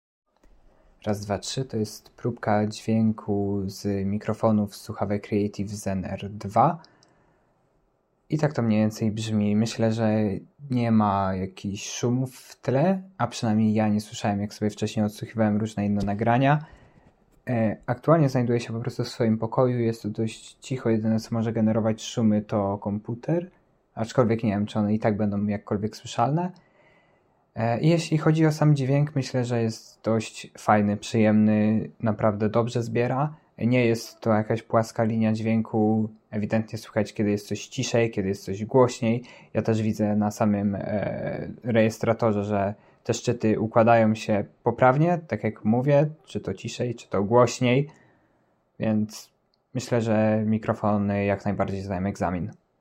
Mikrofony natomiast przewyższyły moje oczekiwania. Ich jakość jest bardzo fajna, nie zbierają szumów i bardzo poprawnie rejestrują głos.
Creative-Zen-Air-2-dzwiek-mikrofonow.mp3